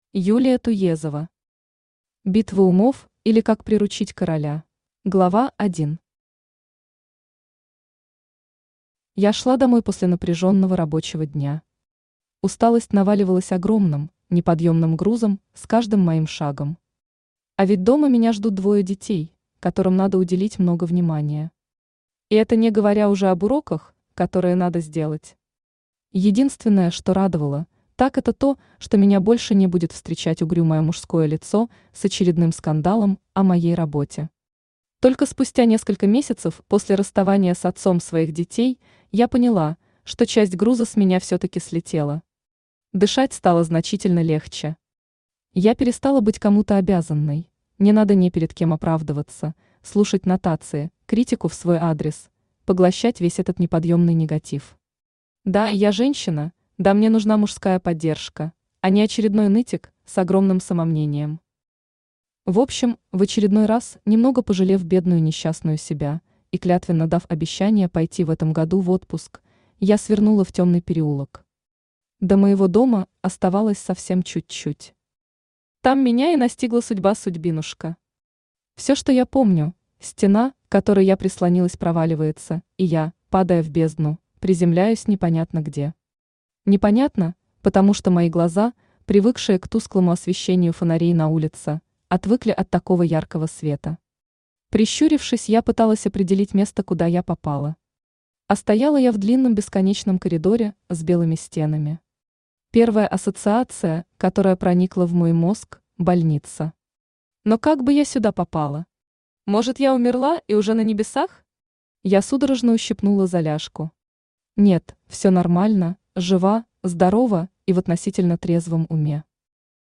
Аудиокнига Битва умов, или Как приручить короля | Библиотека аудиокниг
Aудиокнига Битва умов, или Как приручить короля Автор Юлия Александровна Туезова Читает аудиокнигу Авточтец ЛитРес.